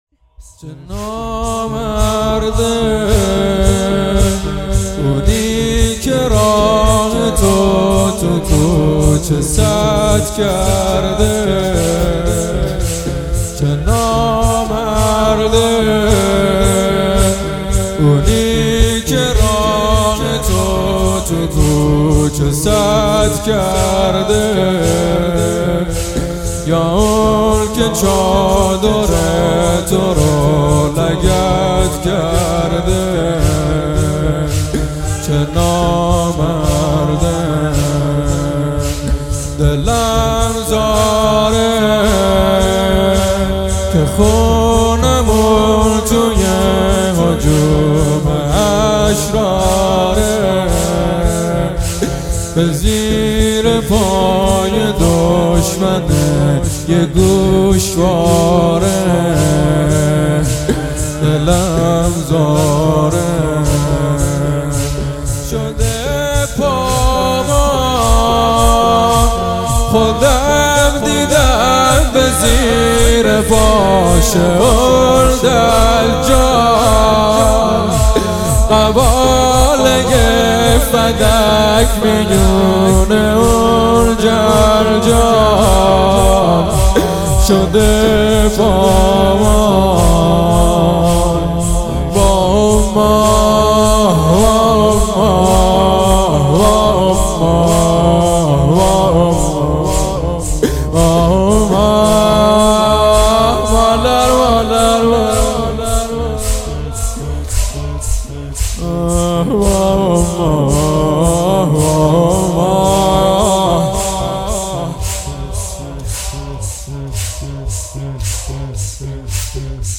زمینه مقتلی
شهادت حضرت زهرا سلام اللله علیها فاطمیه دوم 1399